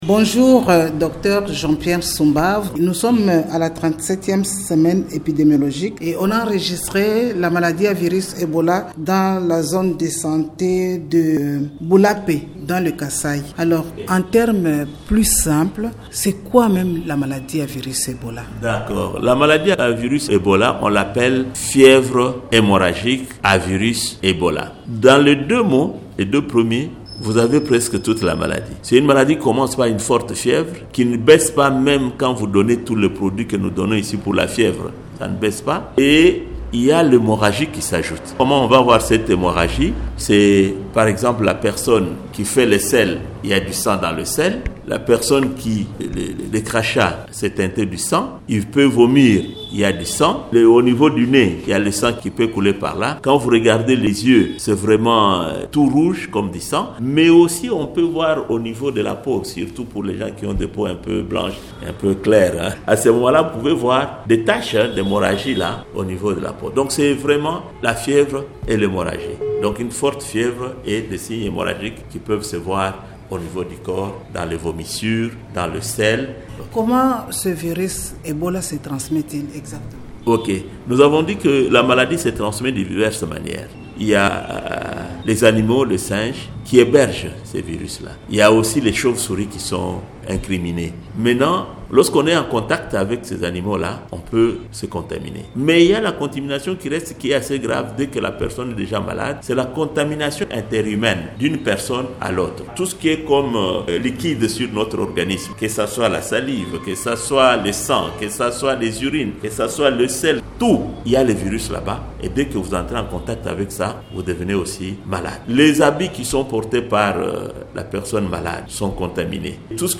Dans un entretien